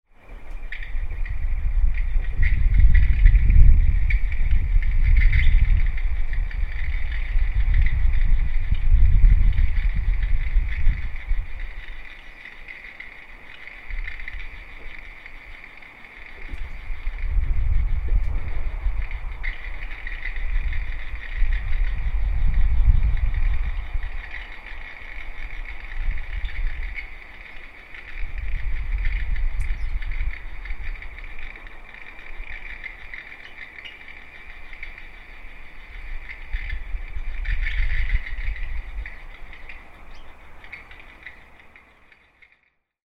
Broken Alarm
The sound of a broken house alarm down our street